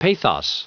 Prononciation du mot pathos en anglais (fichier audio)
Prononciation du mot : pathos